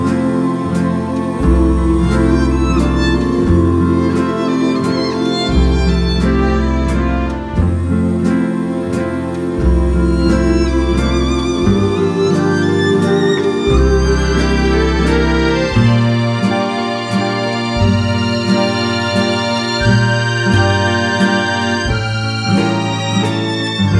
Crooners